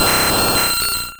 Cri de Ptéra dans Pokémon Rouge et Bleu.